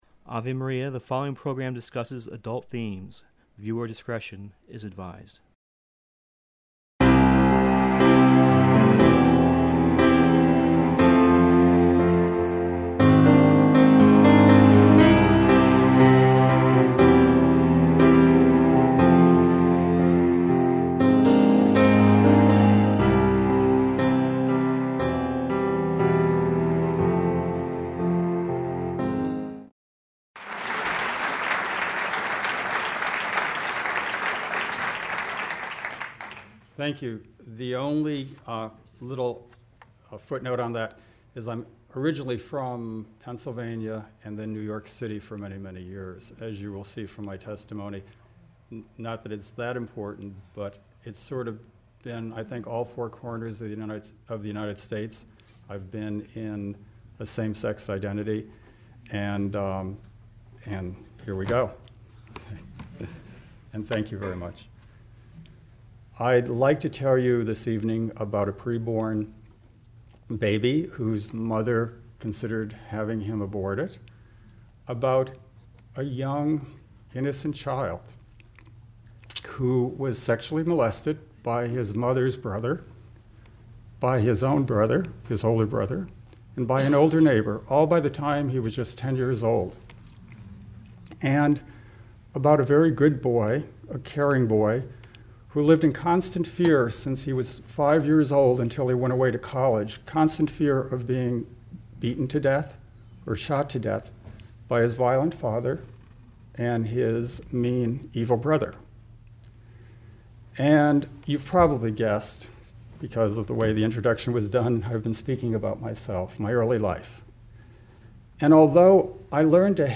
The first part of the eighth talk at the 2013 International Courage Conference in Mundelein, IL